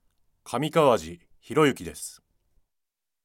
ボイスサンプルはこちら↓名前